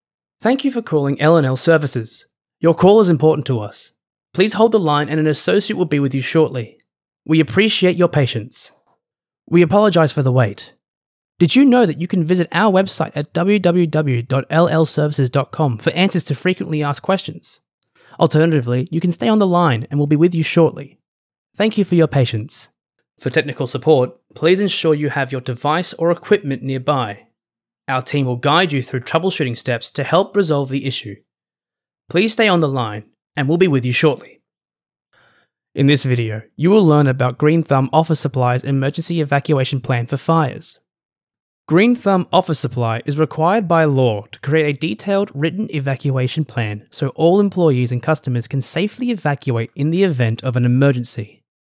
My voice is warm & genuine .